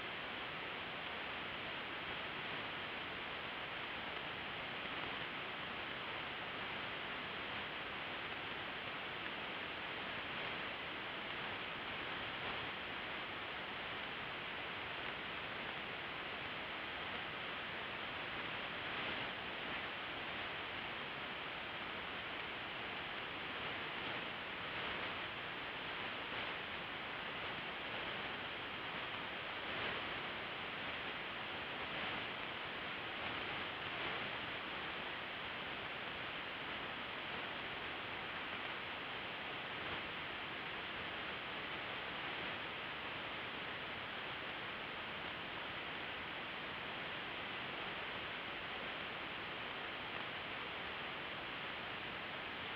For this daytime experiment, we used the Radio Jove Receiver tuned to approximately 20.1 MHz.  The antenna was a 3-element Yagi pointed 111 degrees true (no tracking was used).
Listen for weak L-bursts (swishing sound).